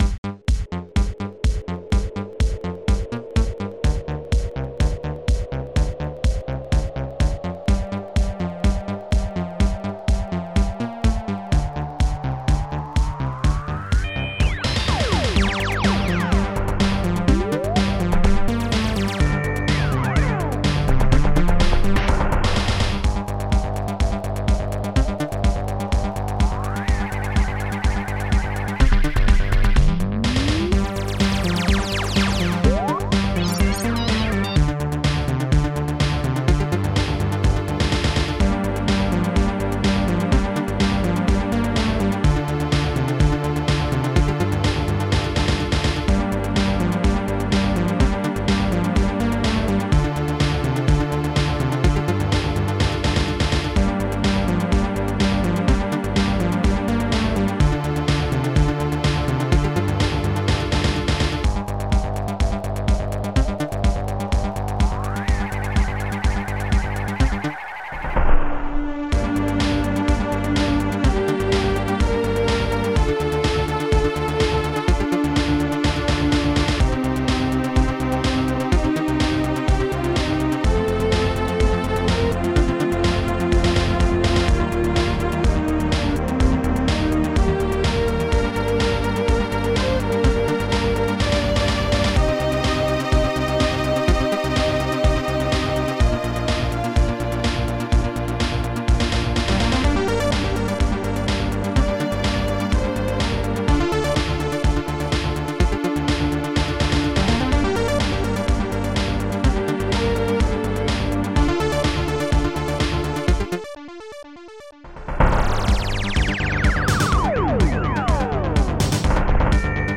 ST-07:lead-brass1
ST-01:bass12
ST-02:bassdrum.gated
ST-06:spacesound3
ST-05:lazershot
ST-05:hihatclose.4
ST-06:cleansynth